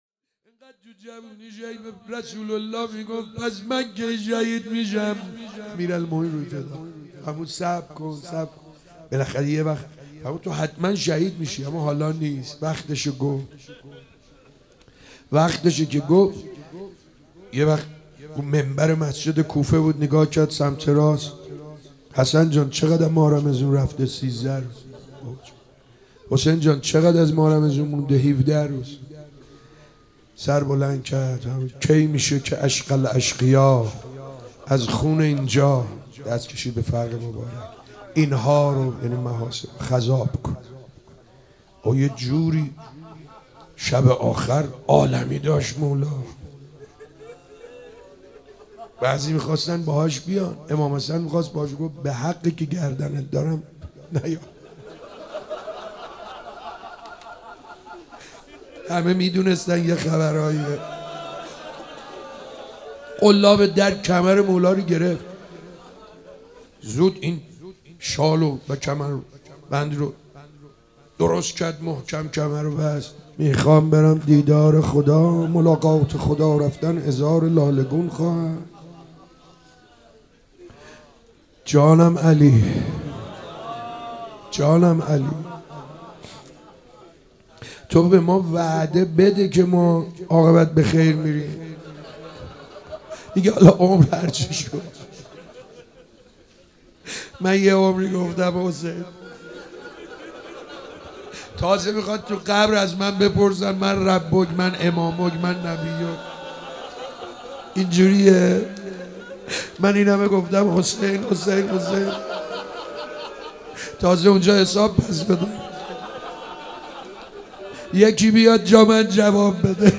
روز عرفه 9 شهریور - روضه